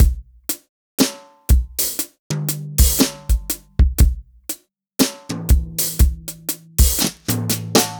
Unison Jazz - 2 - 120bpm.wav